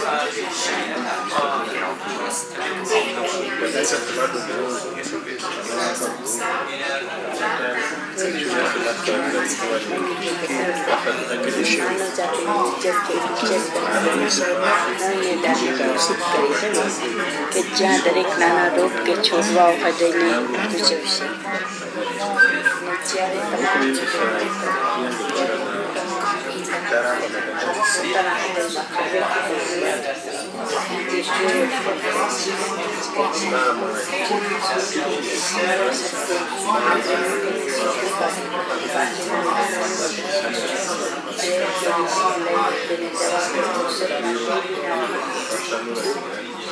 Sound sculpture, art, installation.